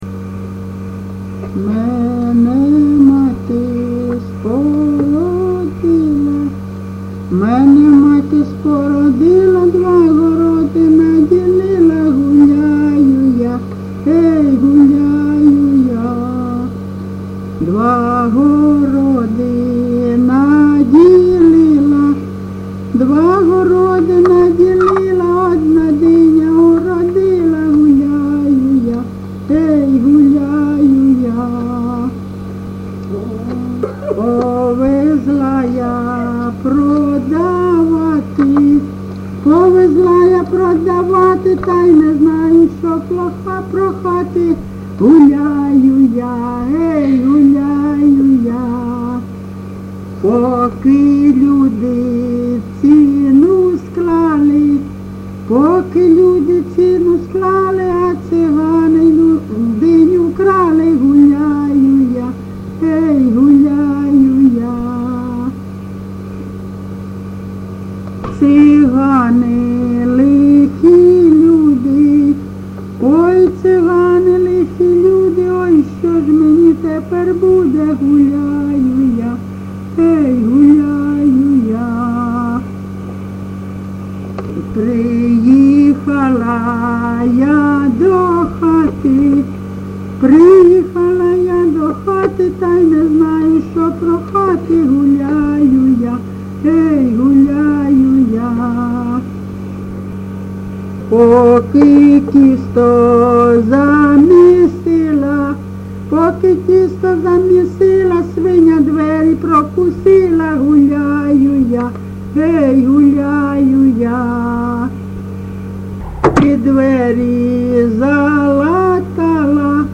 ЖанрЖартівливі
Місце записус. Калинове Костянтинівський (Краматорський) район, Донецька обл., Україна, Слобожанщина